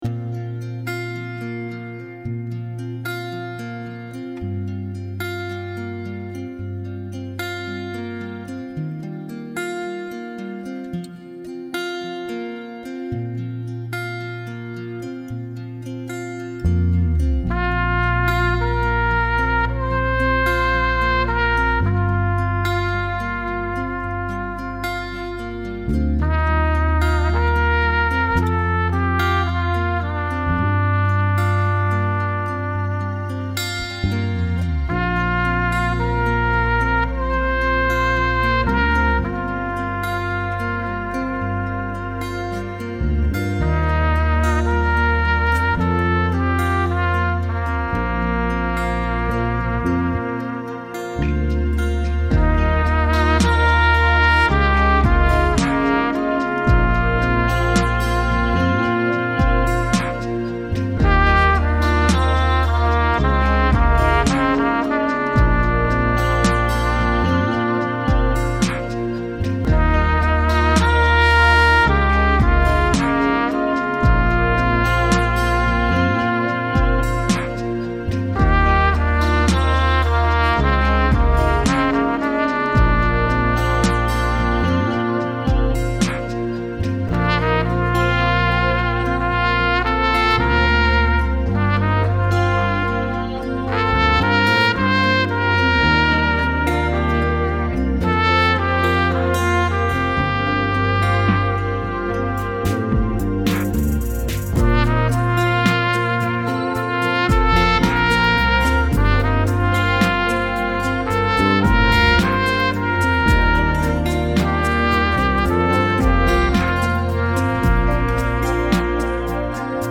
Playalongs